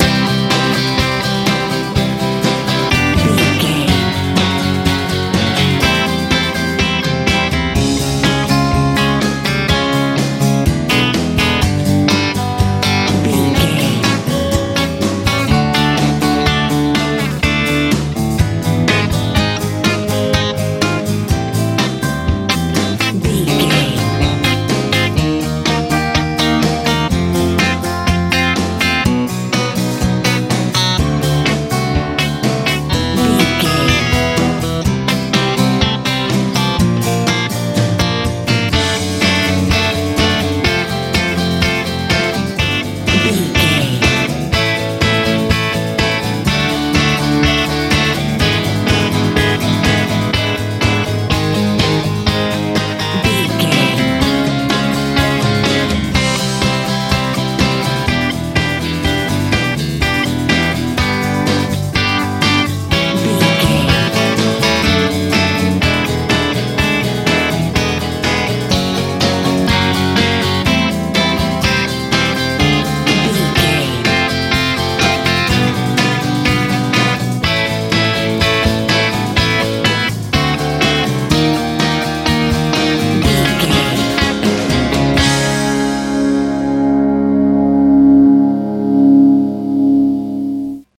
uplifting rock feel
Ionian/Major
electric guitar
acoustic guitar
bass guitar
drums
energetic